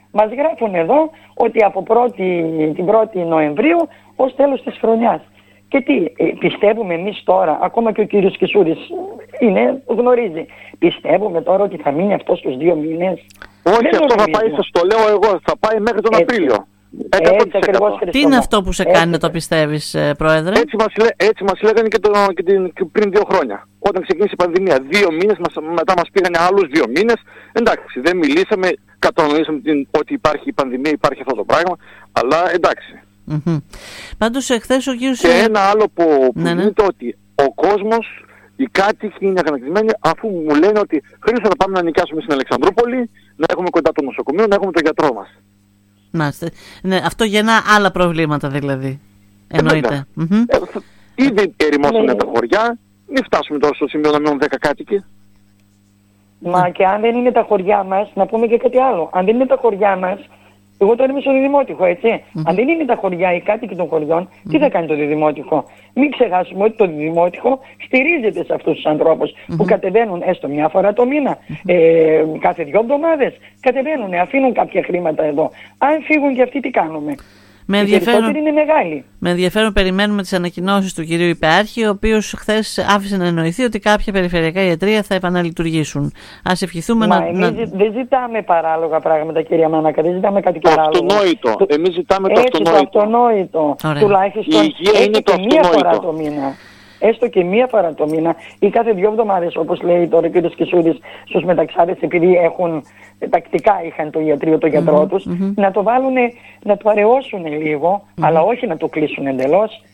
Η αγανάκτηση είναι έκδηλη,  στις δηλώσεις της προέδρου της Δημοτικής ενότητας Διδυμοτείχου Ευαγγελίας Καραγιάννη  και του προέδρου της κοινότητας Μεταξάδων Χρήστου Κισσούδη  που συνυπογράφουν την επιστολή.
Μίλησαν σήμερα στην ΕΡΤ Ορεστιάδας παρουσιάζοντας τα προβλήματα που δημιουργεί μια τέτοια απόφαση και τα παράπονα που εισπράττουν καθημερινά από κατοίκους που σωματικά και οικονομικά είναι ανήμποροι να έρθουν στην πόλη.